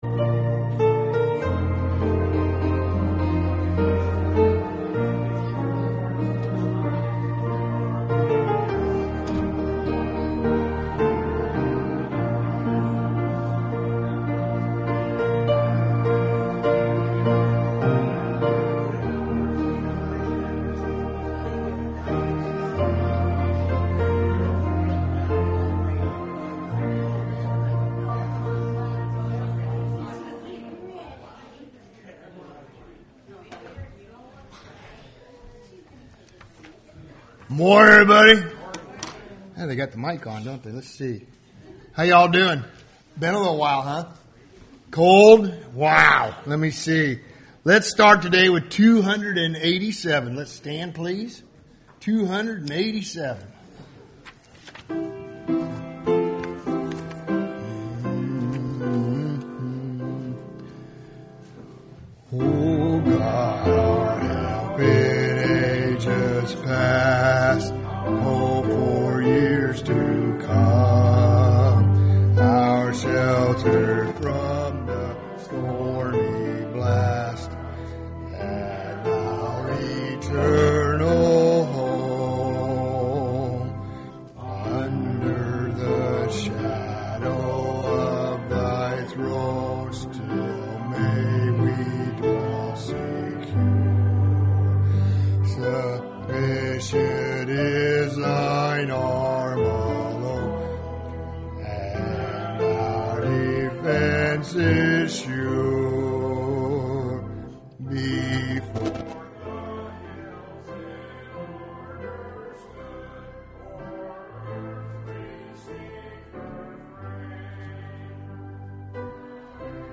Service Type: Sunday Morning Service « Is the Local Church a Priority in Your Life?